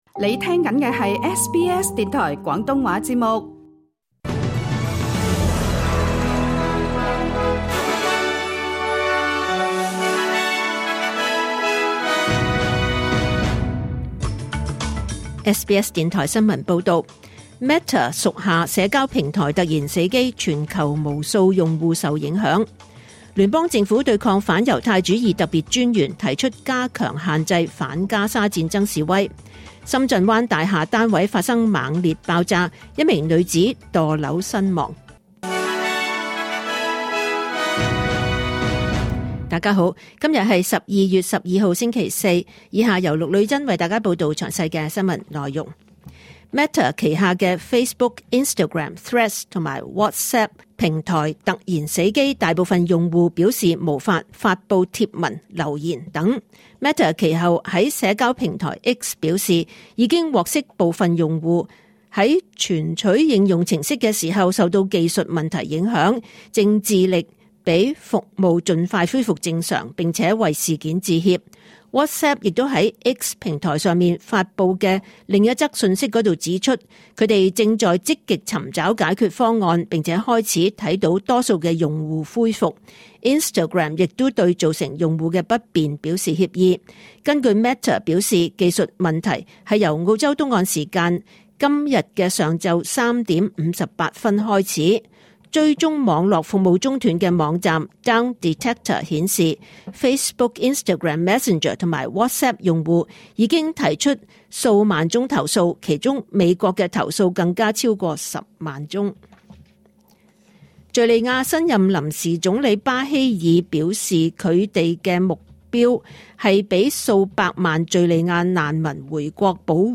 2024 年 12 月 12 日 SBS 廣東話節目詳盡早晨新聞報道。